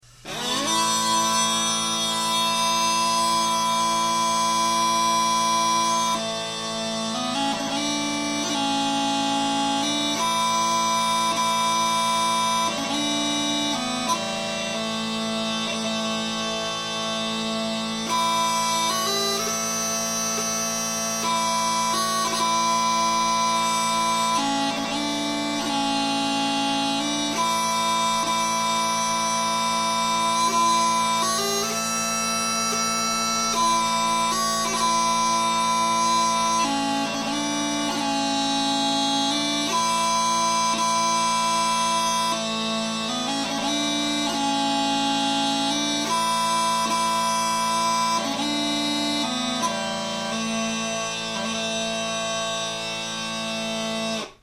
Bagpipes and Bagpipers for the fresno area and central san joaquin valley